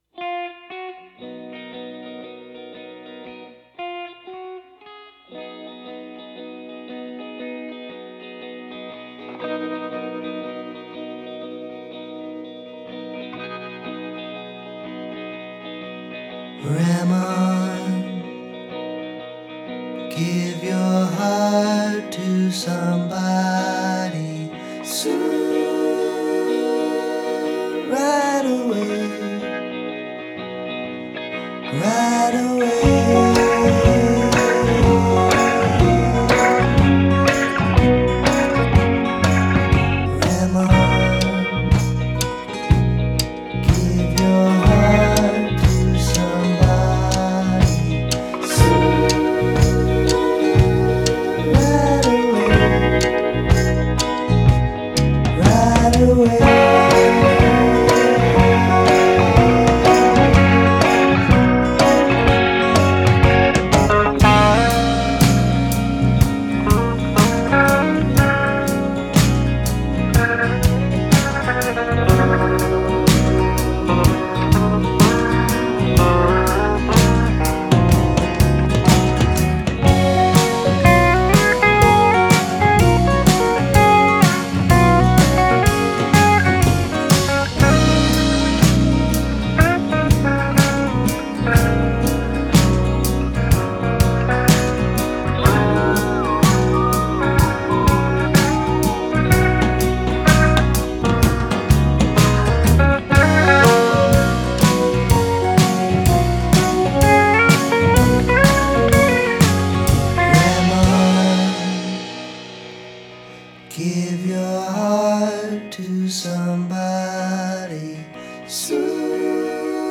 stays closer to the original